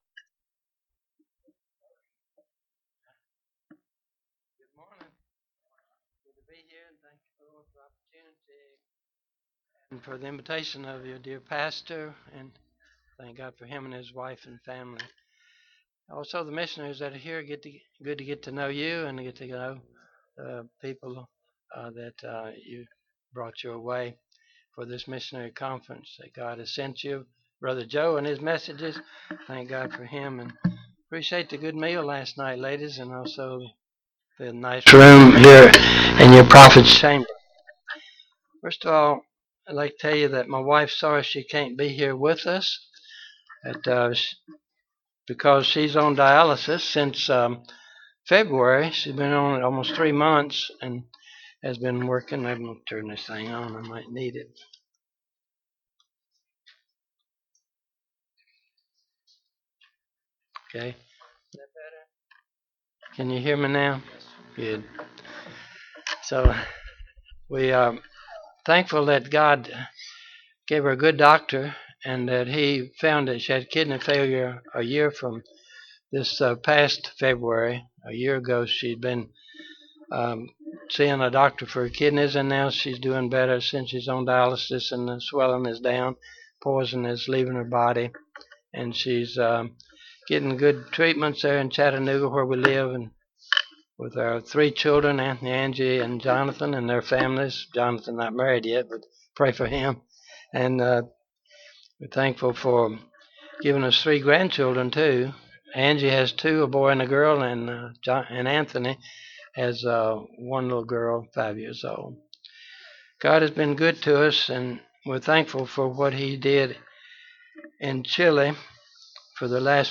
John 9:1-4 Service Type: Mission Conference Bible Text